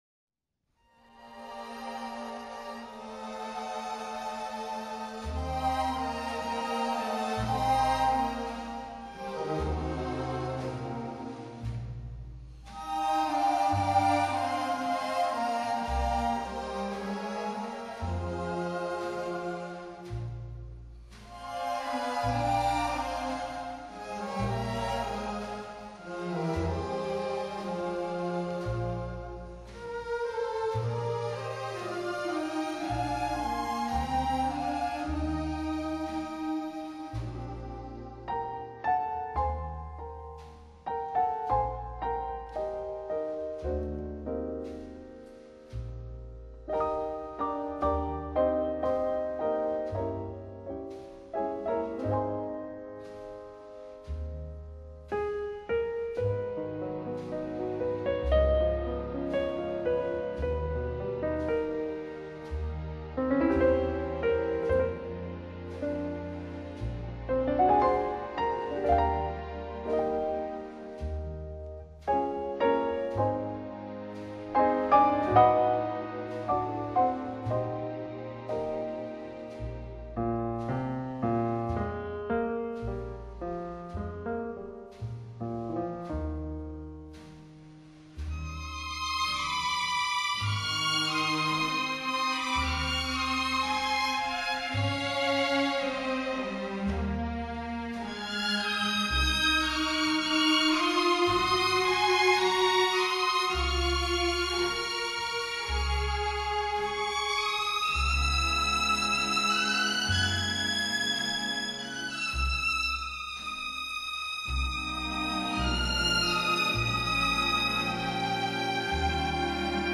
【轻音乐专辑】
录制方式：ADD